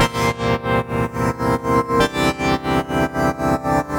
Index of /musicradar/sidechained-samples/120bpm
GnS_Pad-MiscB1:8_120-C.wav